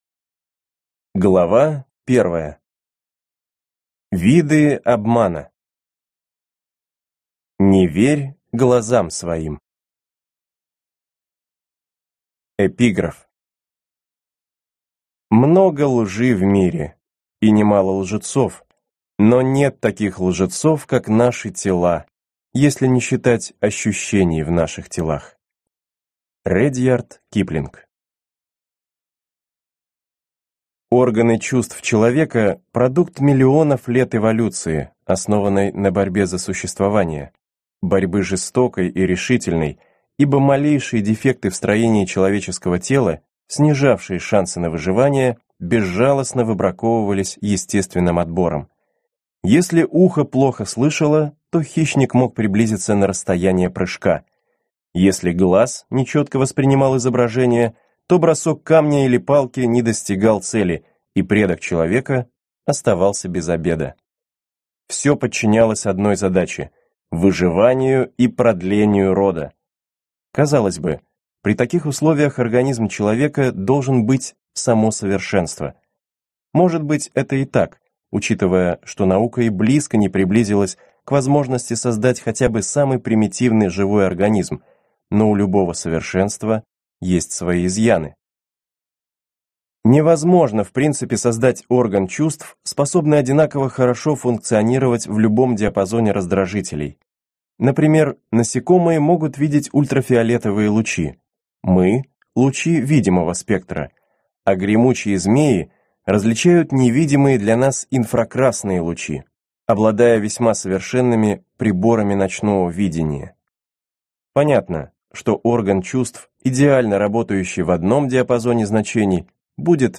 Аудиокнига Психология обмана | Библиотека аудиокниг
Прослушать и бесплатно скачать фрагмент аудиокниги